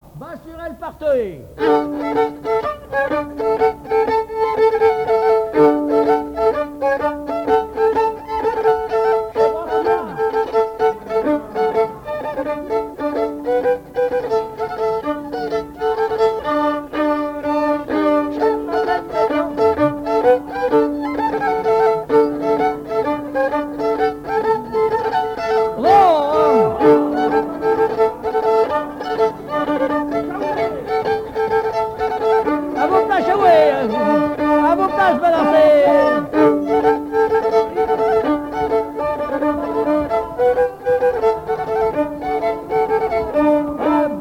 Quadrille - Pastourelle
danse : quadrille : pastourelle
Pièce musicale inédite